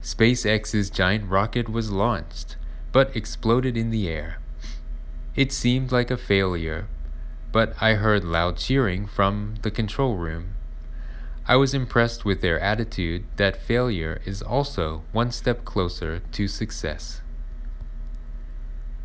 英会話ロングアイランドカフェの英作文会話4/26 - シャドーイング用音声↑↑↑レッスン後には毎回添削文と音声をお送りしてます。